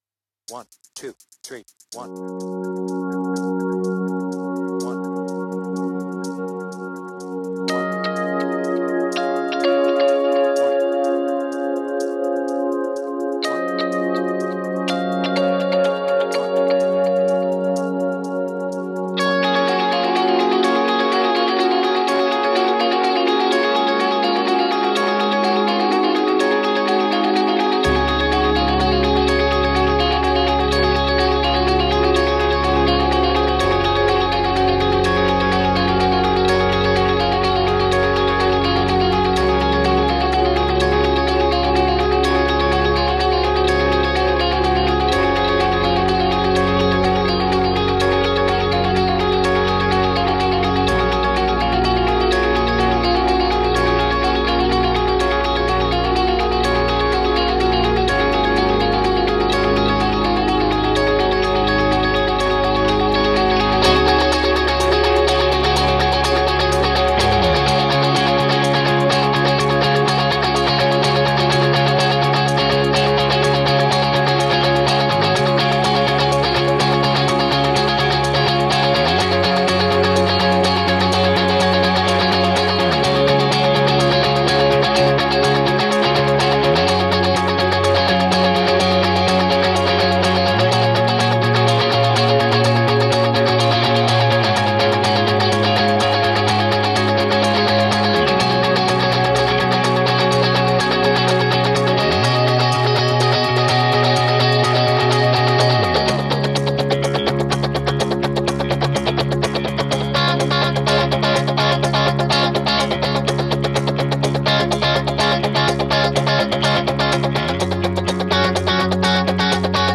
BPM : 125
Tuning : Eb
Without vocals